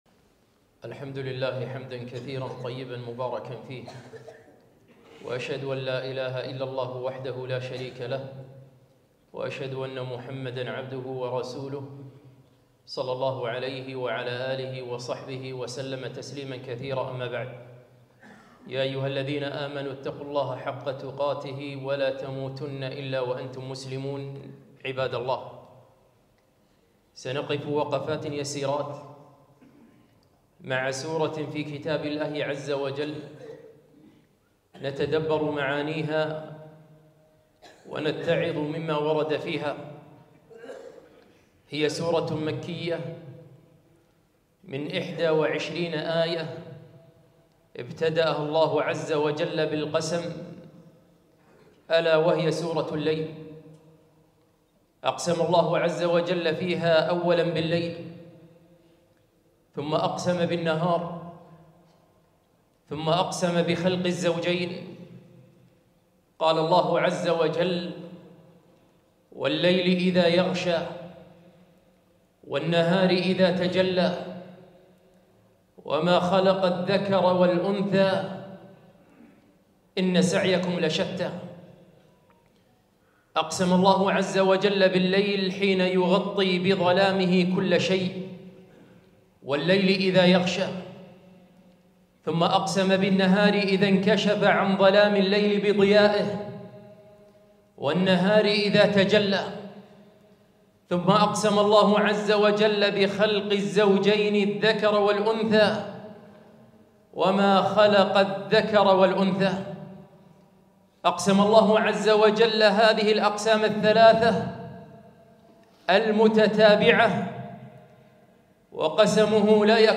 خطبة - سورة الليل